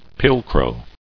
[pil·crow]